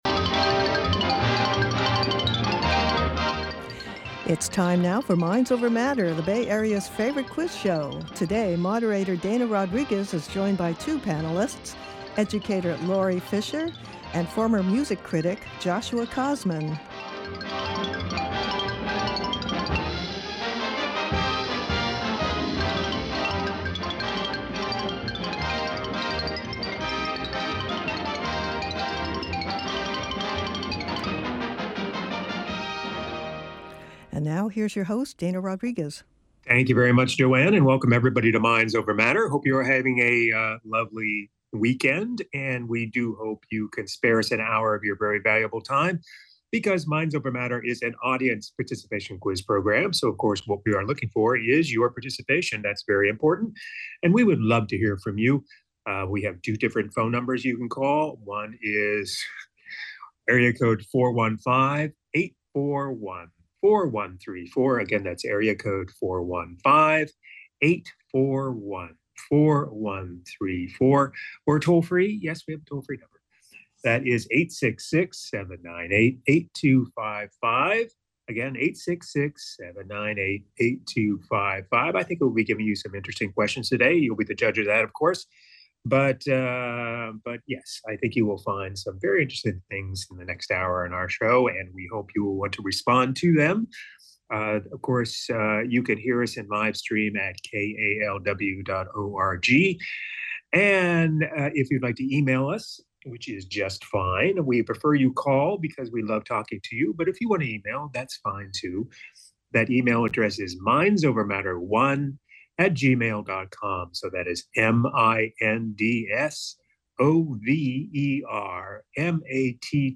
The Bay Area's favorite quiz show!